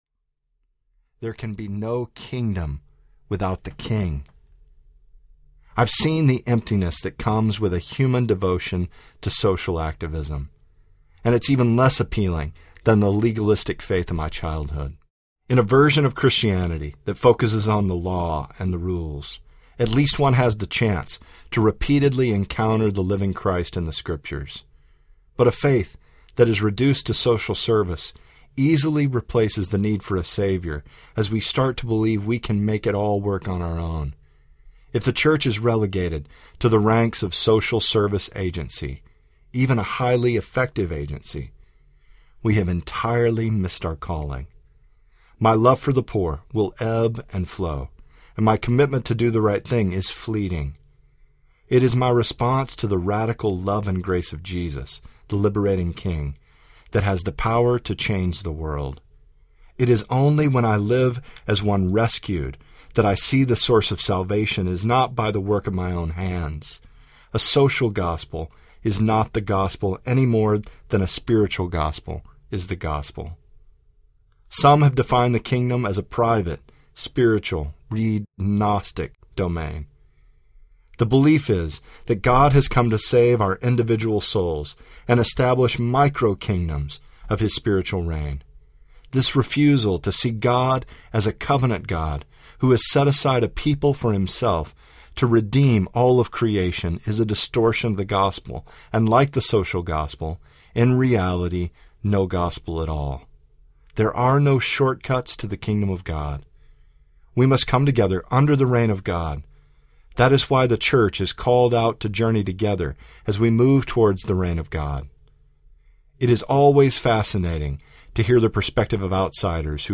The Gospel According to Jesus Audiobook
Narrator